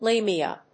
/ˈlemiʌ(米国英語), ˈleɪmi:ʌ(英国英語)/